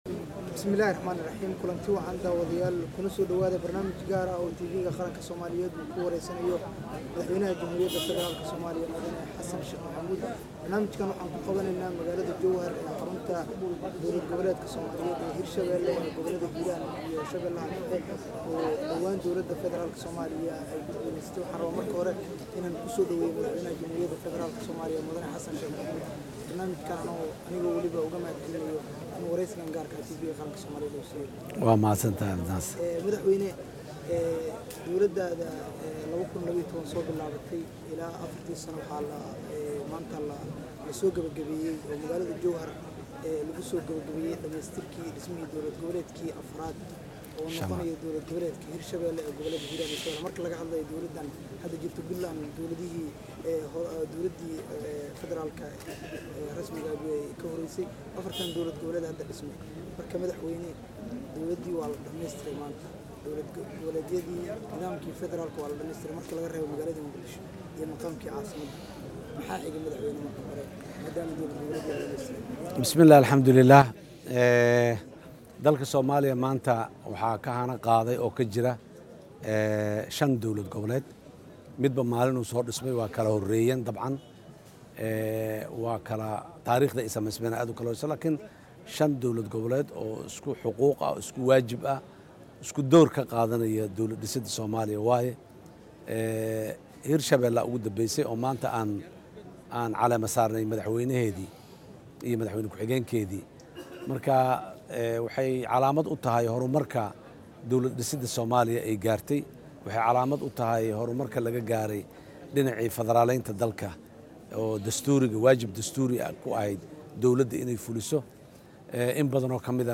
Madaxweynaha Dowladda federaalka ah ee Soomaaliya ayaa wareysi dhinacyo badan taabanayo siiyey taleefishinka Qaranka Soomaaliya , madaxweynaha ayaa wareysigan bixiyey